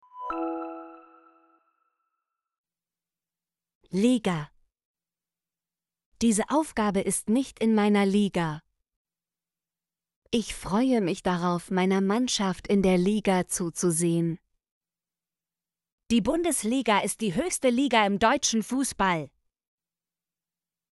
liga - Example Sentences & Pronunciation, German Frequency List